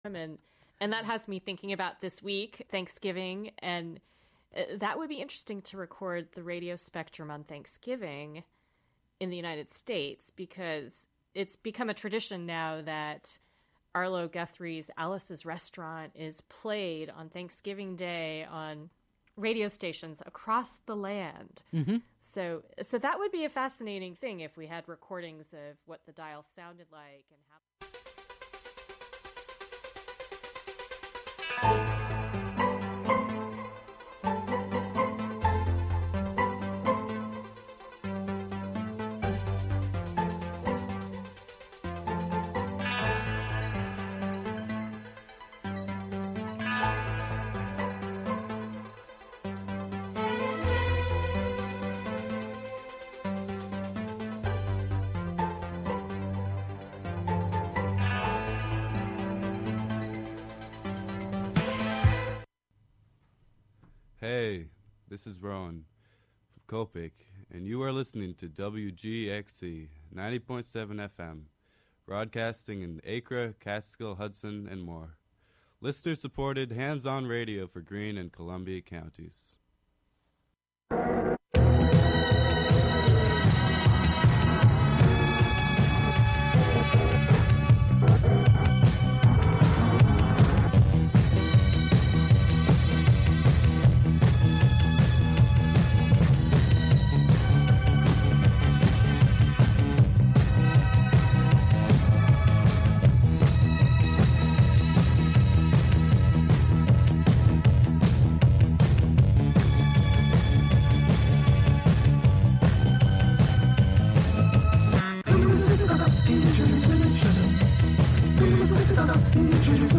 Saturdays the show features weekly episodes of the "I Have Seen Niagara" serial.